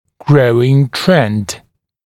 [‘grəuɪŋ trend][‘гроуин трэнд]растущая тенденция